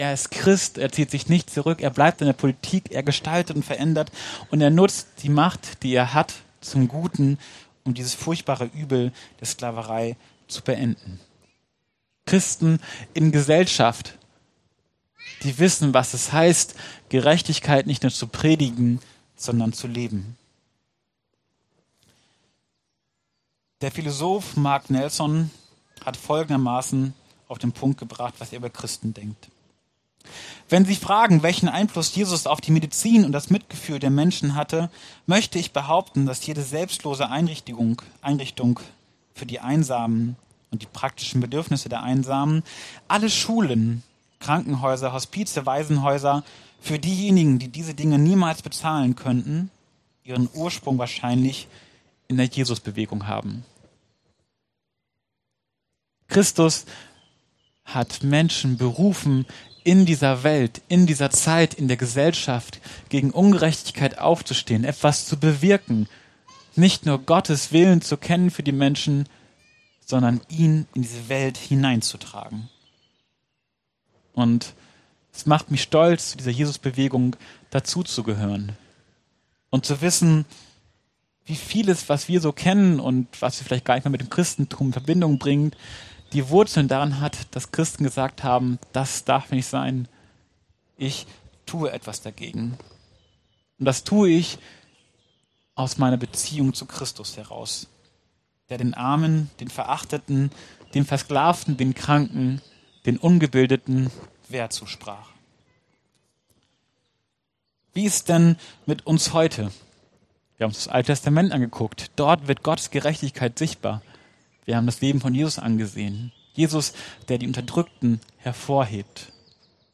Leider ist die Aufnahme der Predigt unvollständig. Es sind nur die letzten 3 Minuten der Predigt zu hören und anschließend ein Interview zum Thema „Gerechtigkeit anwenden“.